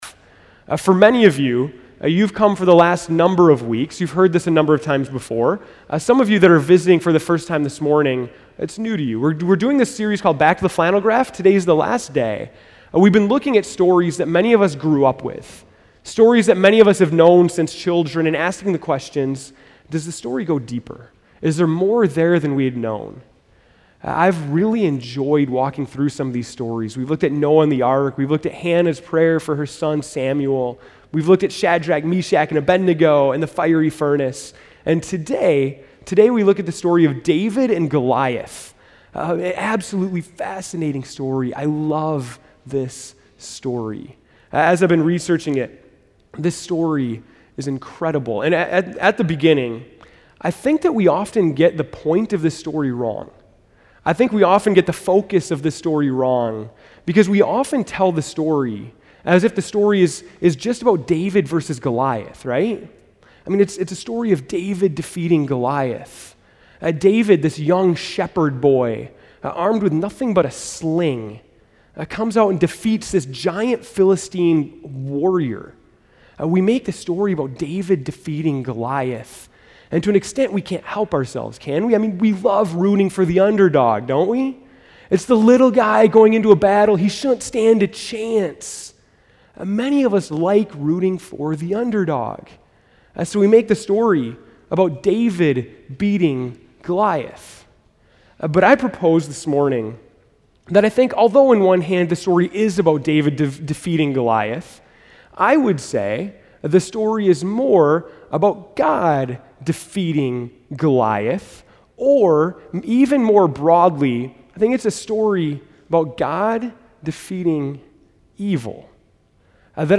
August 25, 2013 (Morning Worship)